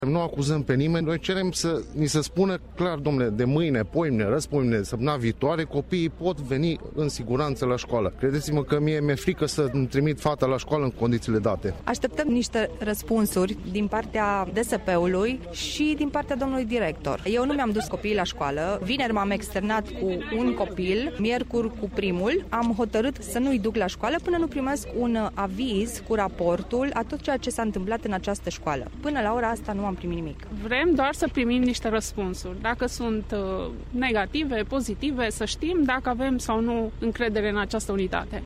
Părinţii elevilor sunt însă îngrijoraţi. Ei s-au adunat aseară în faţa liceului şi spun că nu îşi vor mai trimite copiii la şcoală până când nu vor şti cu certitudine că aceştia sunt în siguranţă: